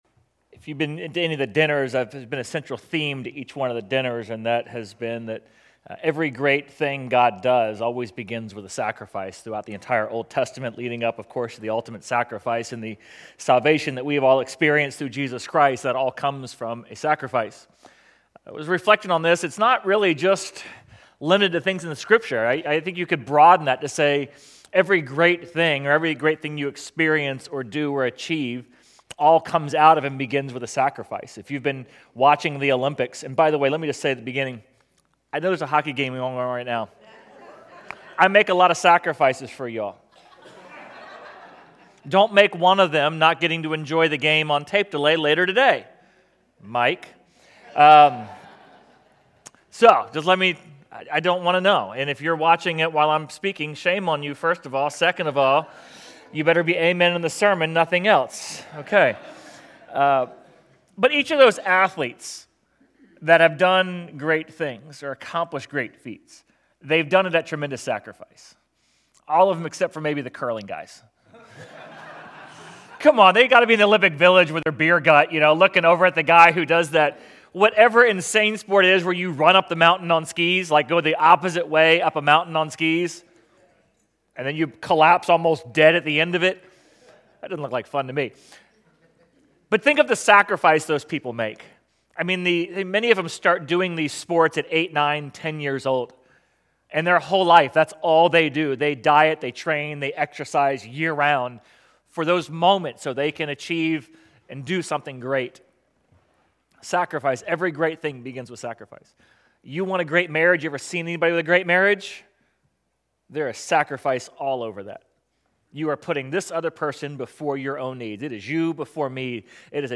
Sermon Audio/Video | Essential Church